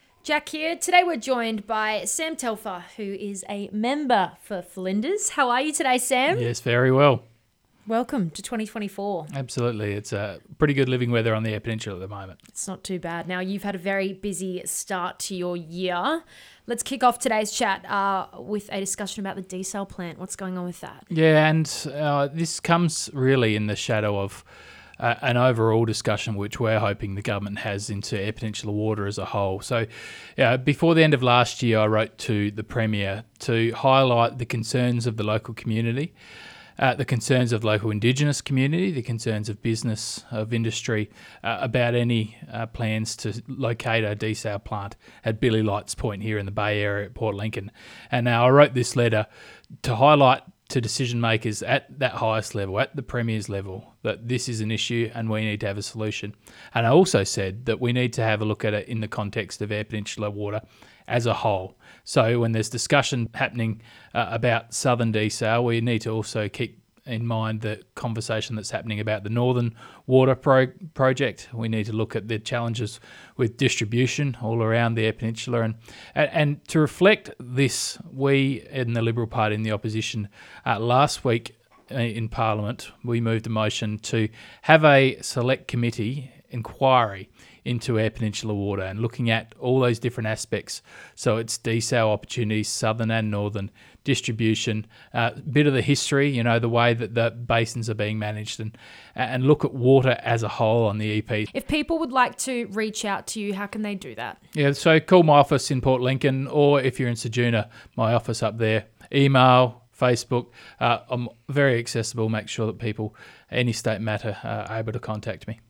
If you’re not familiar with our commercial radio stations, I have a monthly spot on 5CC and Magic 89.9, where I give my thoughts on the major issues around the EP, with this month being all about the EP desal plant.